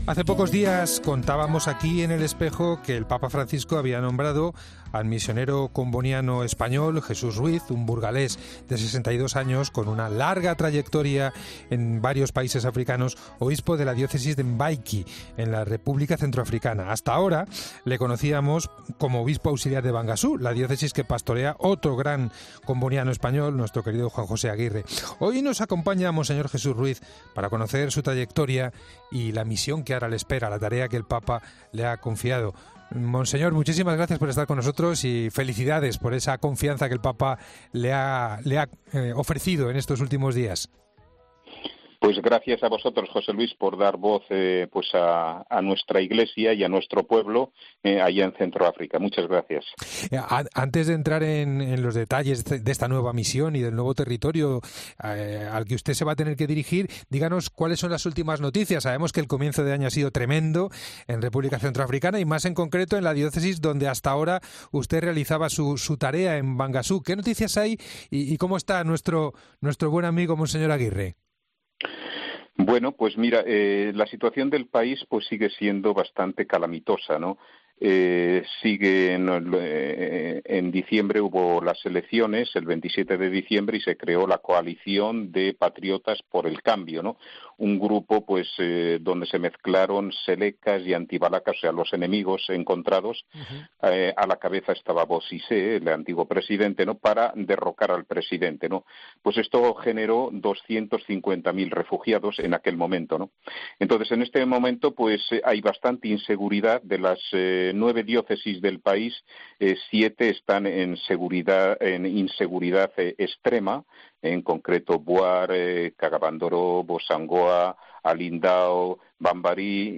Para analizar y compartir la misión que le espera, esta nueva tarea que le ha confiado el Papa Francisco, mons. Ruiz atiende a los micrófonos de El Espejo.